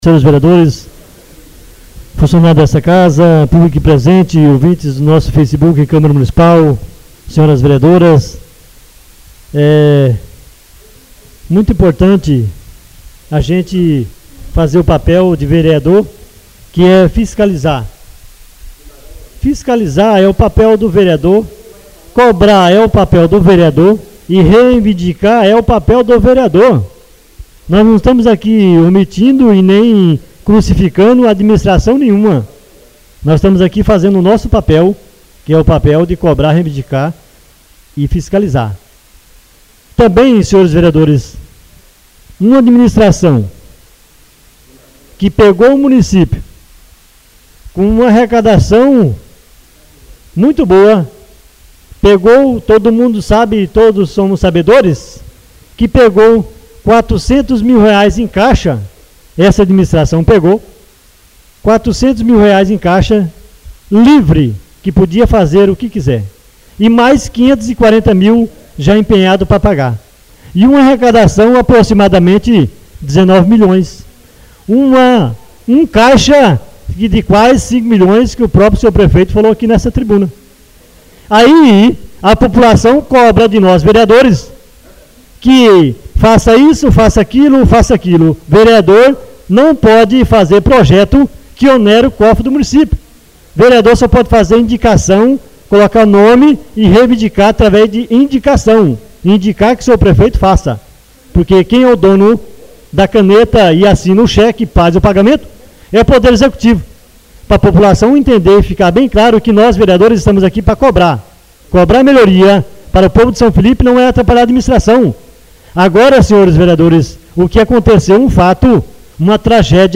Oradores das Explicações Pessoais (27ª Ordinária da 3ª Sessão Legislativa da 6ª Legislatura)